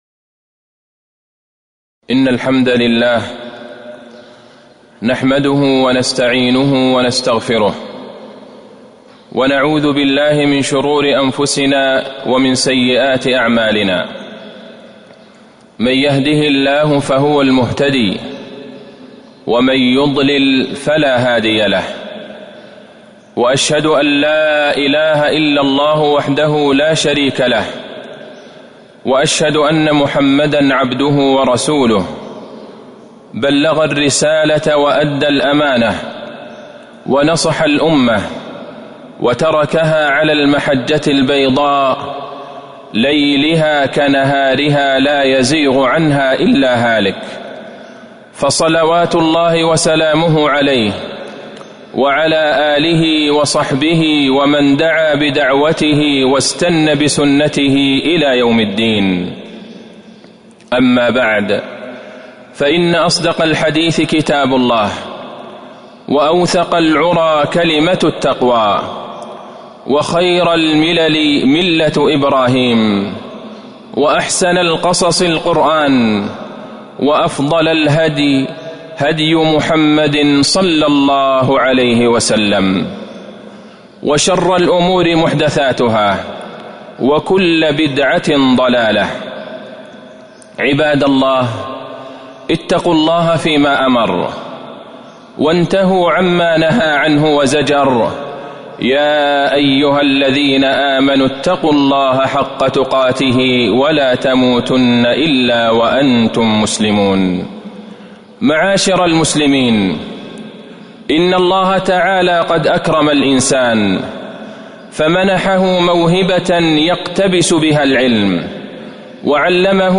تاريخ النشر ٢٦ محرم ١٤٤٣ هـ المكان: المسجد النبوي الشيخ: فضيلة الشيخ د. عبدالله بن عبدالرحمن البعيجان فضيلة الشيخ د. عبدالله بن عبدالرحمن البعيجان فضل العلم وأهله